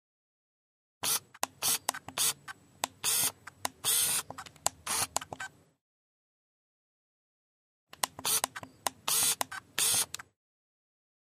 Camera Digital Servo Lens, Zoom x2